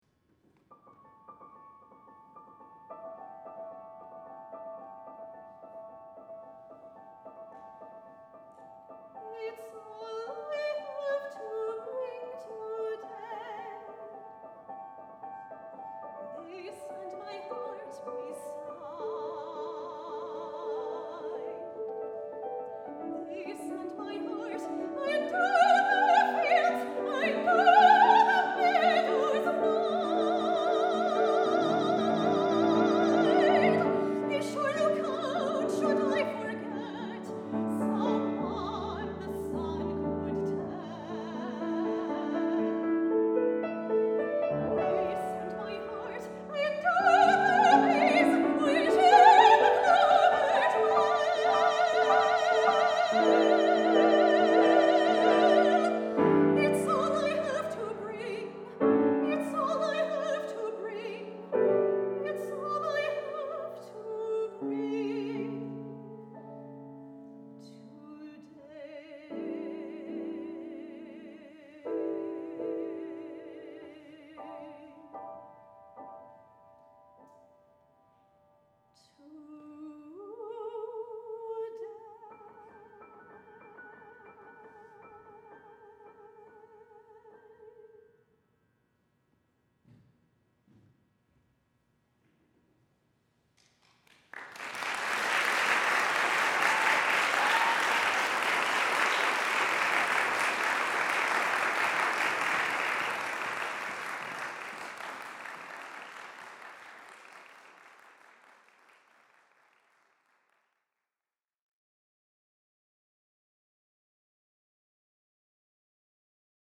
for Soprano and Piano (2014)